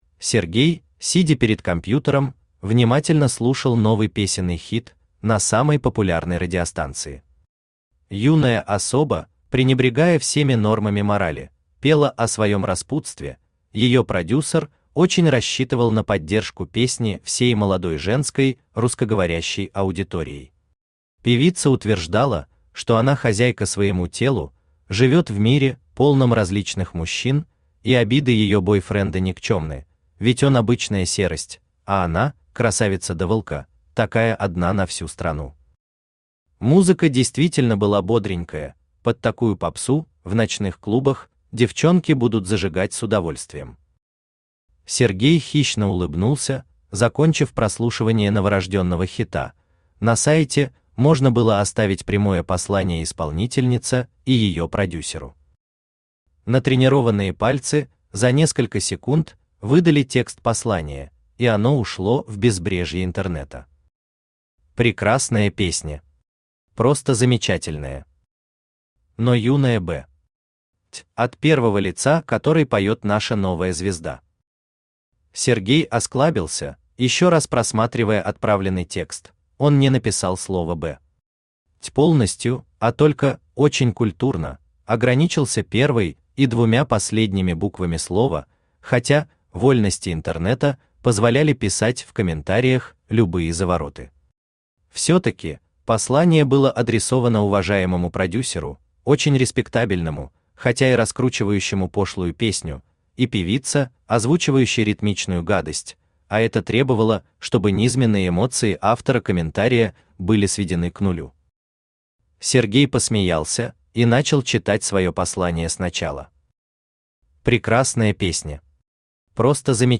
Aудиокнига Русский разведчик Автор Алексей Николаевич Наст Читает аудиокнигу Авточтец ЛитРес.